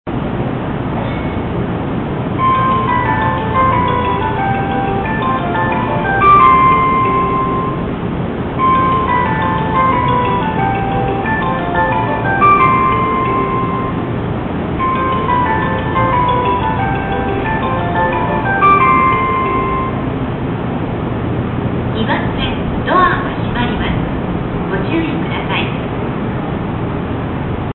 ATOS第１号線ということで、女声の放送しかありません。
このメロディは、以前府中本町で使われていたメロディです。